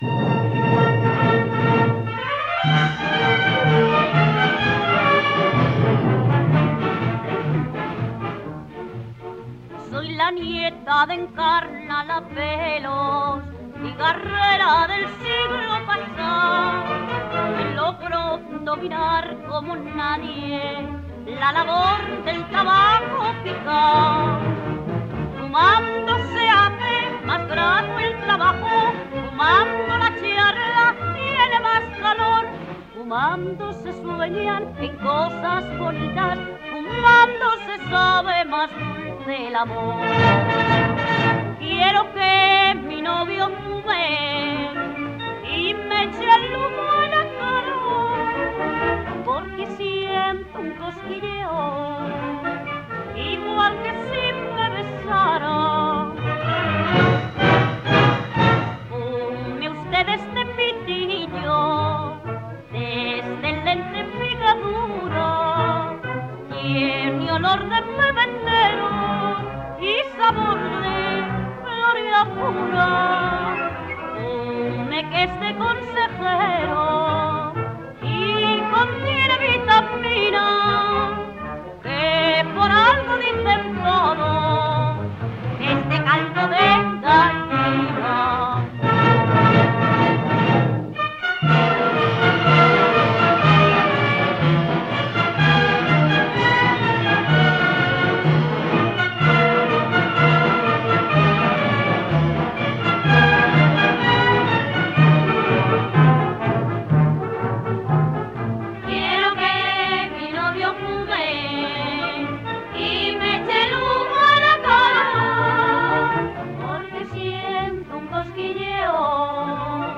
pasodoble de los cigarros
78 rpm.